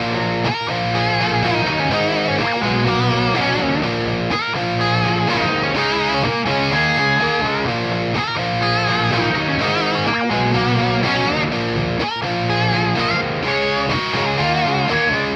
车库摇滚吉他主音
Tag: 125 bpm Punk Loops Guitar Electric Loops 2.58 MB wav Key : A